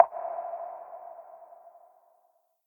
shutter.ogg